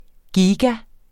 Udtale [ ˈgiːga ]